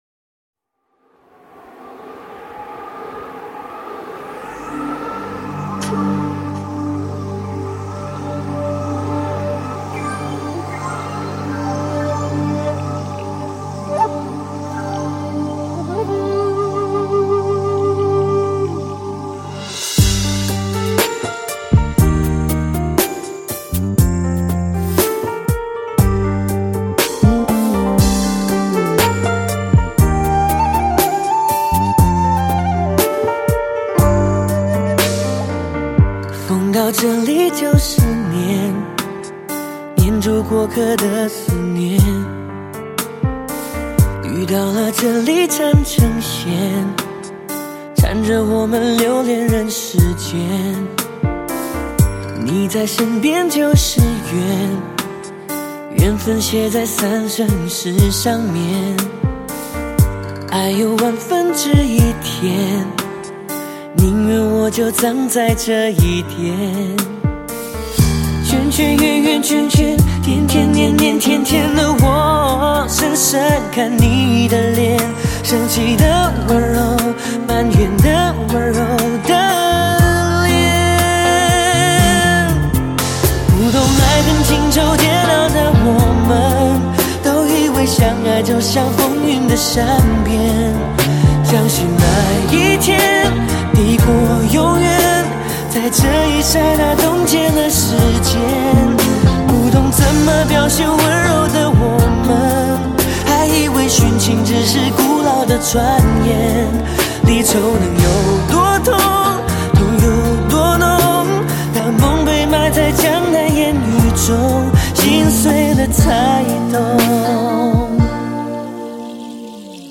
将100KHz宽频带/24bit音频信息载入
音色更接近模拟(Analogue)声效
强劲动态音效中横溢出细致韵味